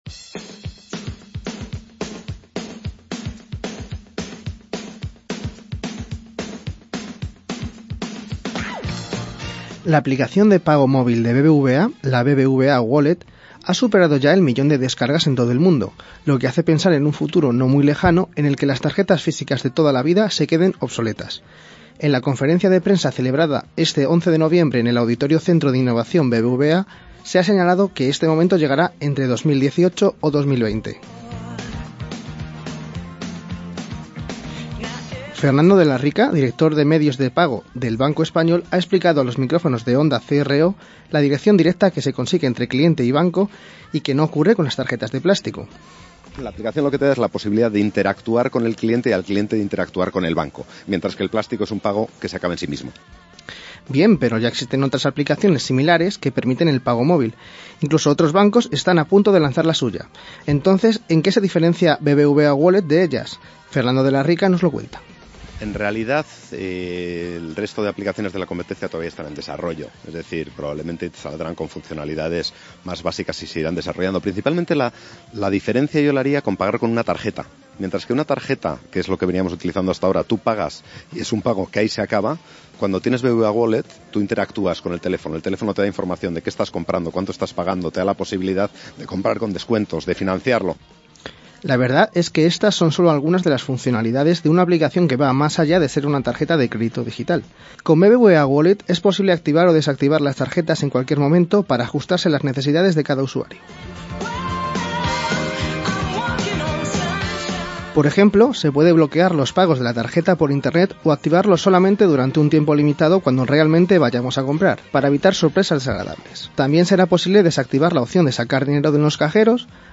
La aplicación de pago móvil de BBVA, la BBVA Wallet, ha superado ya el millón de descargas en todo el mundo, lo que hace pensar en un futuro no muy lejano en el que las tarjetas físicas de toda la vida se queden obsoletas. En la conferencia de prensa celebrada este 11 de noviembre en el Auditorio Centro de Innovación BBVA, se ha señalado que este momento llegará entre 2018 y 2020.